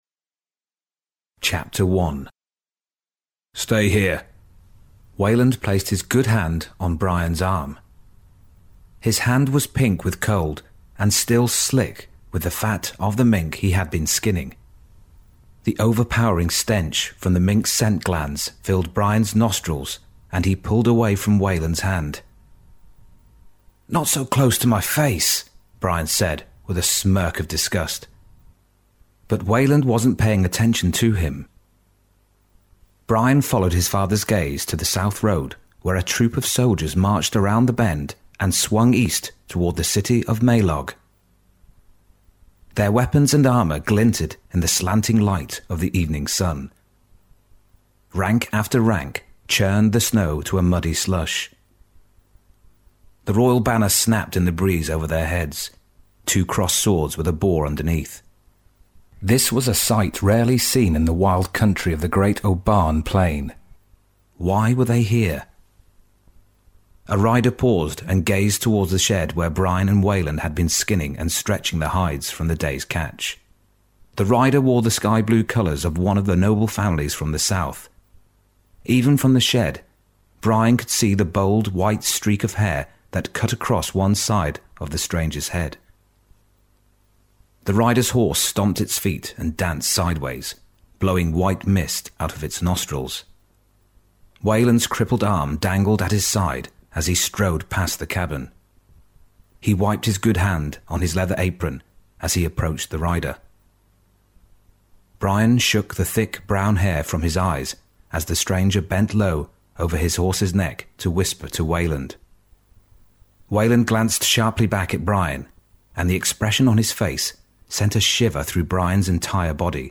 Audio Book Resources Audio Sample